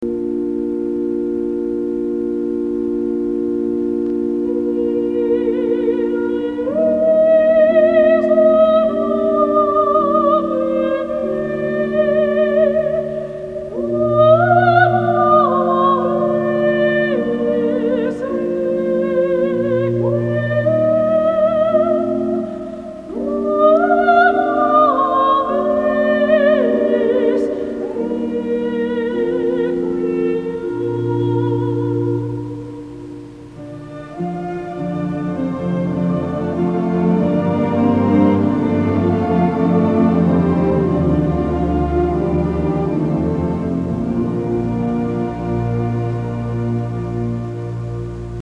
Oratorium
Requiem
(Carlos Kalmar, Tonkünstlerorchester NÖ)